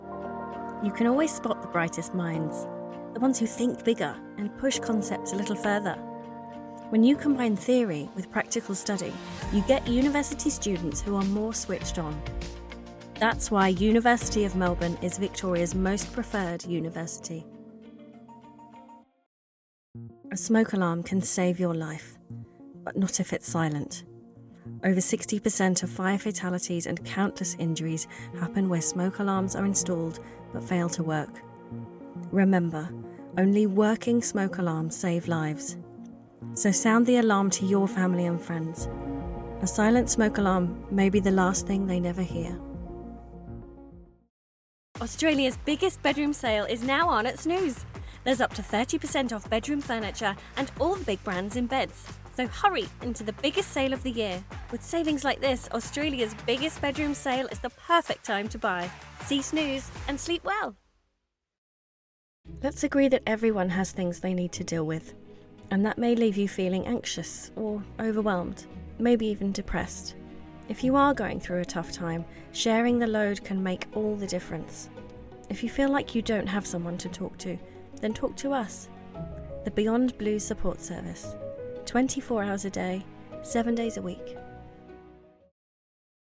Female
English (British)
Yng Adult (18-29), Adult (30-50)
Warm and friendly with an instinct for finding the funny!
My voice is warm but I can be dry and droll (my favorite) or quirky and playful.
A Variety Of Commercials
All our voice actors have professional broadcast quality recording studios.